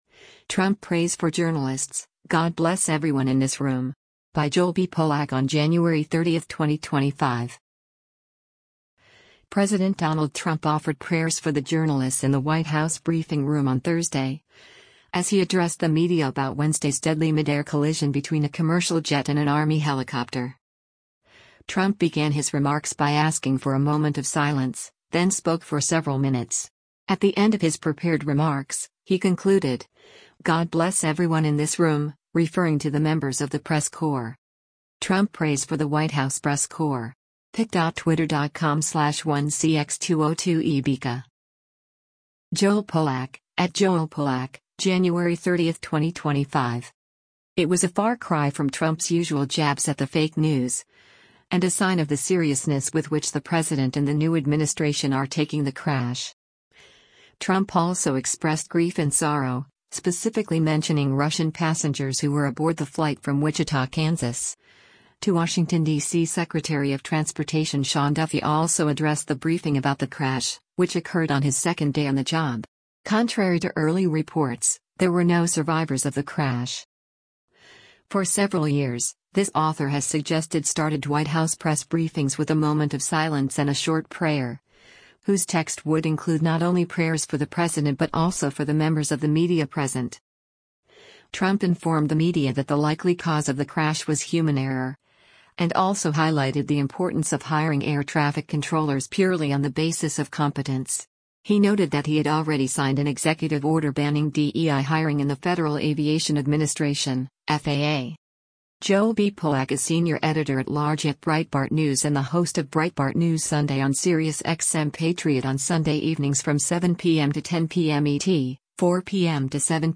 President Donald Trump offered prayers for the journalists in the White House briefing room on Thursday, as he addressed the media about Wednesday’s deadly midair collision between a commercial jet and an Army helicopter.
Trump began his remarks by asking for a moment of silence, then spoke for several minutes.